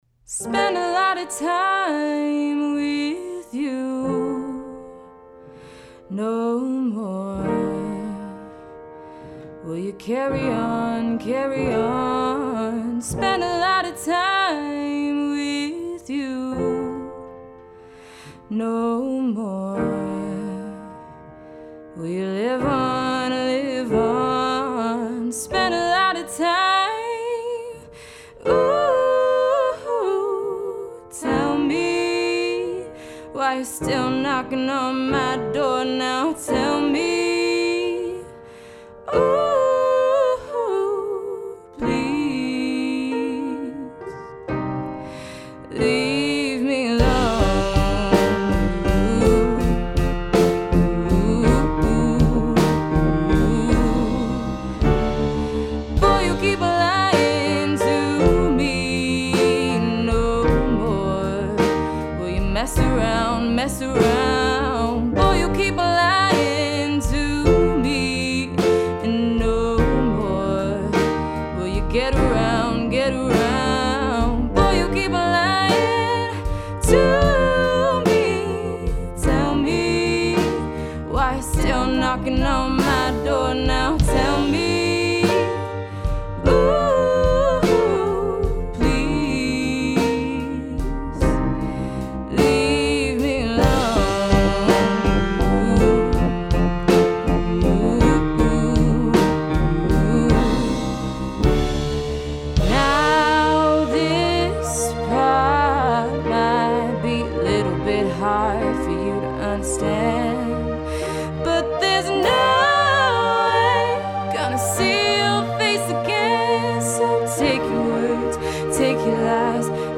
• Emo Singer Songwriter
• Close and room mics